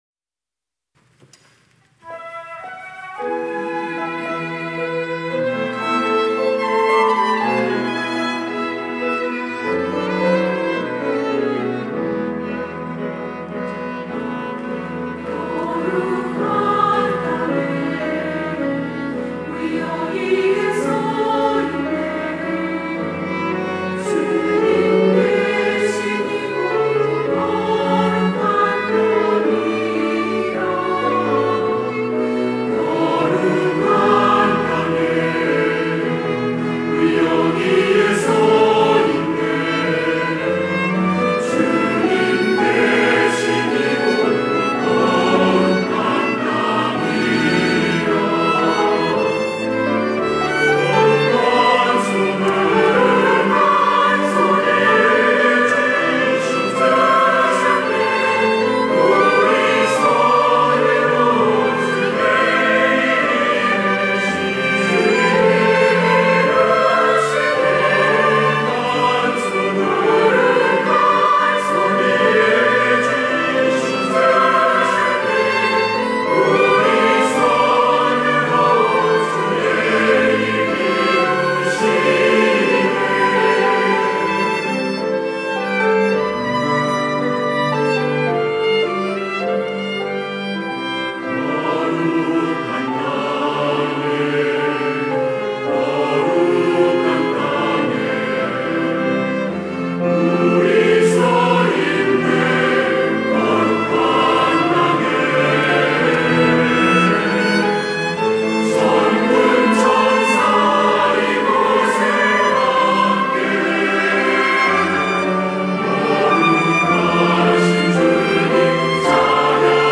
할렐루야(주일2부) - 거룩한 땅에
찬양대